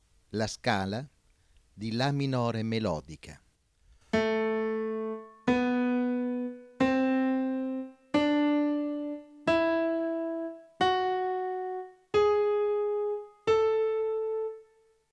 08. Ascolto della scala di La minore melodica.
08_La_m_melodica.wma